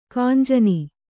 ♪ kānjani